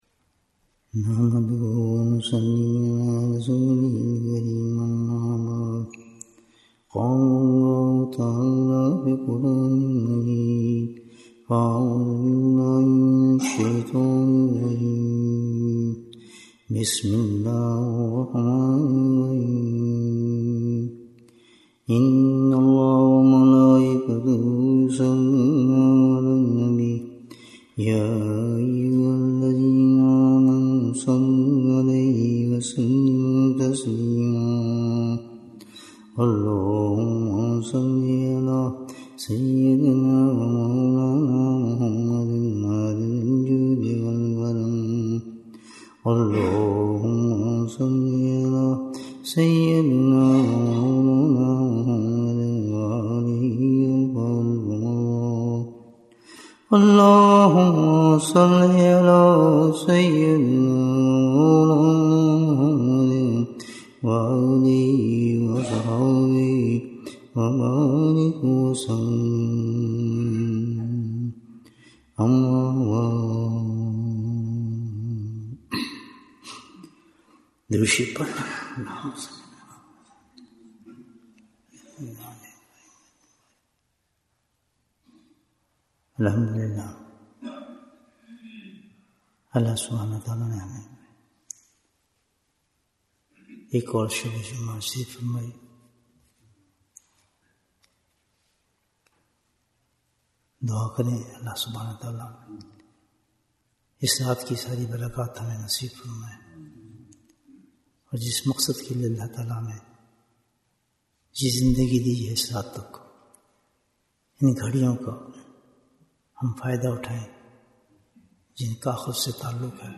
Bayan, 119 minutes28th November, 2024